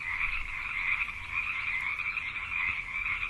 Frogs_04.ogg